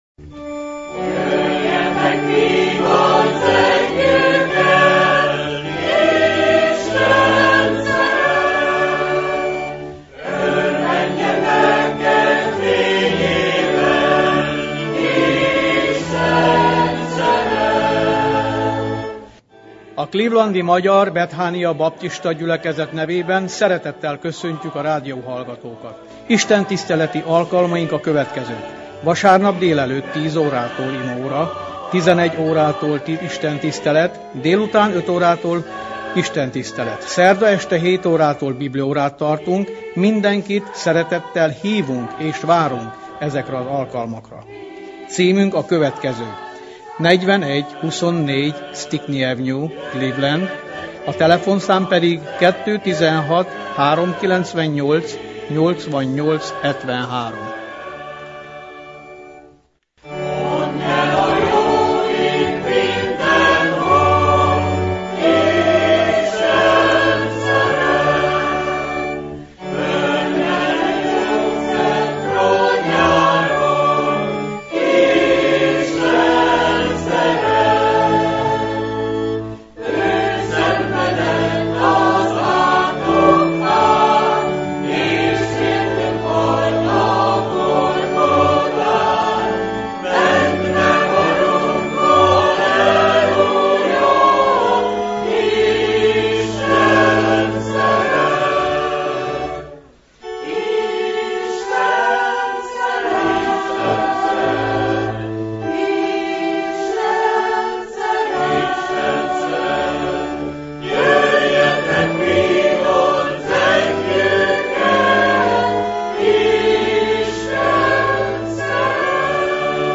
Igét hirdet